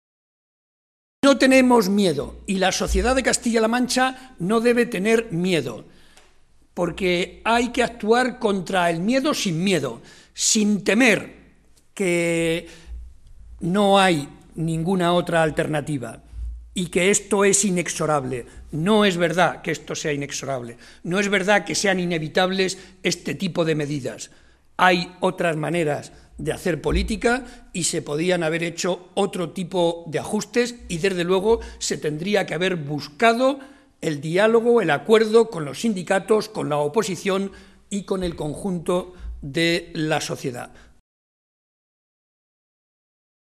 José María Barreda, secretario General del PSOE de Castilla-La Mancha
Cortes de audio de la rueda de prensa